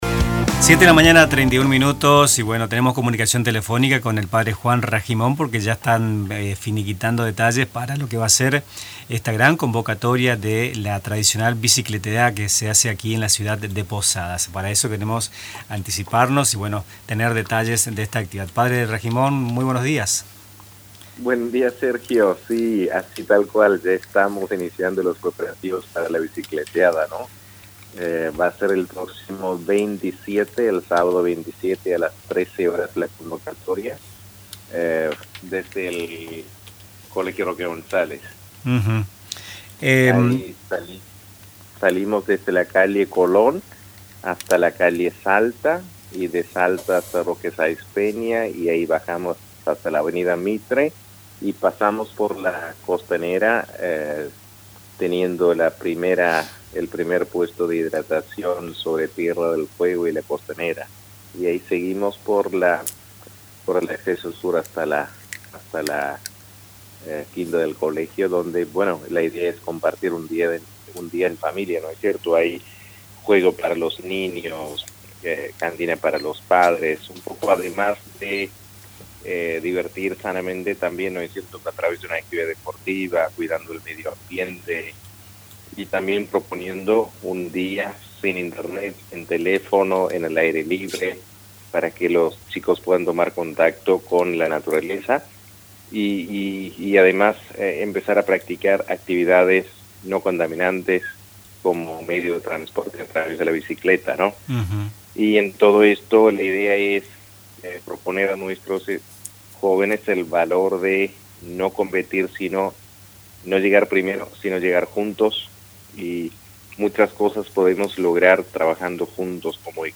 En una entrevista telefónica con Radio Tupa Mbae